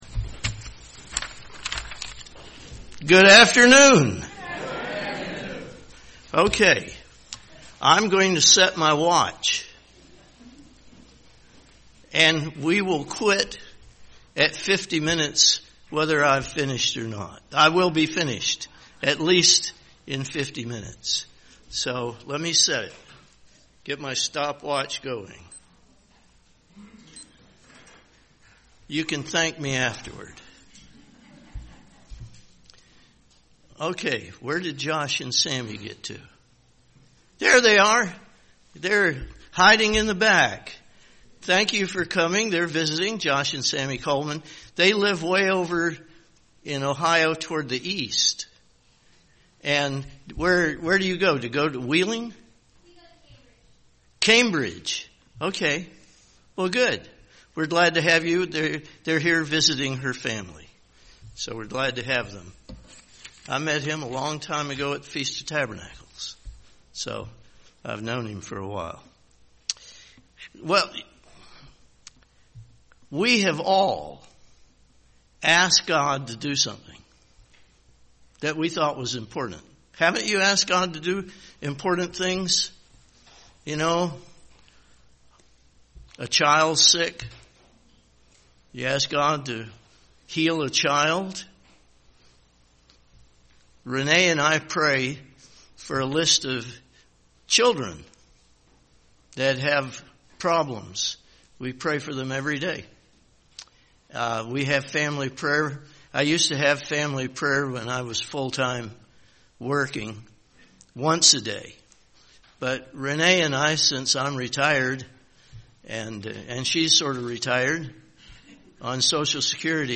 Sermons
Given in Dayton, OH